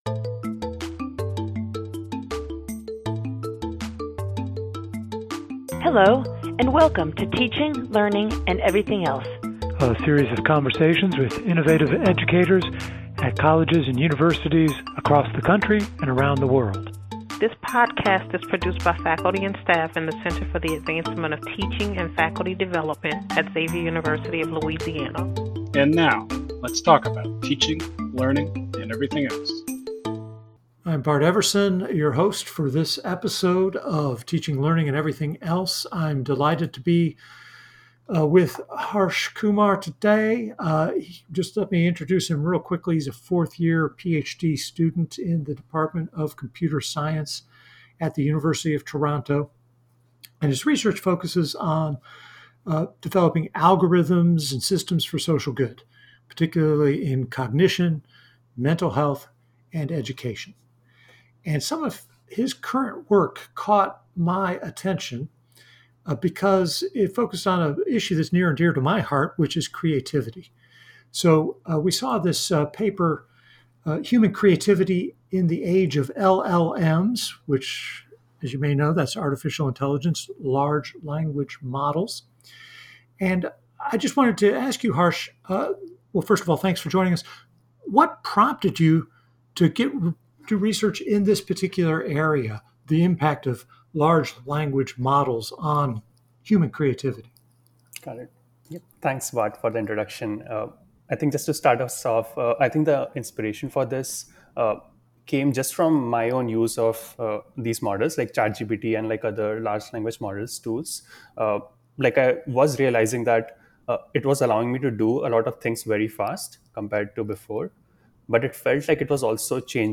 conversations with people who teach in higher education